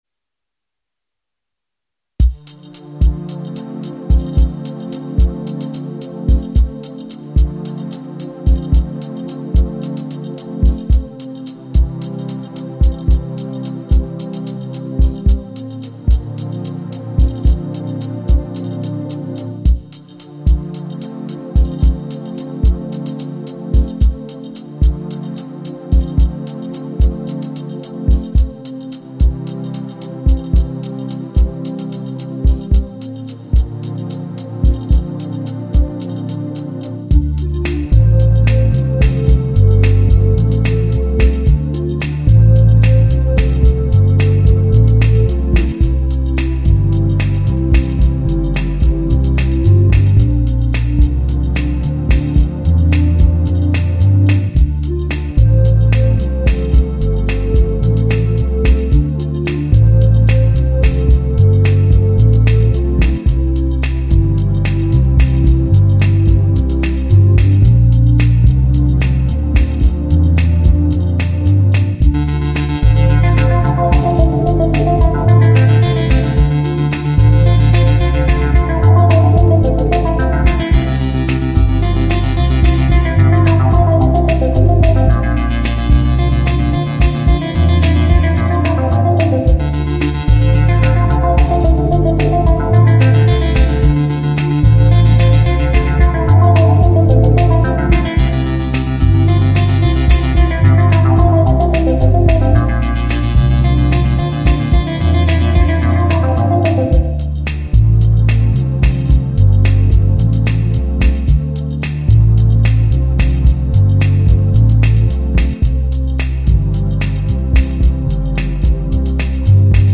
Don't mind the suboptimal sound quality in some of the songs, most of them are intentionally not polished.
Slow motion with RM1X.